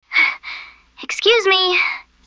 excuse-me2.wav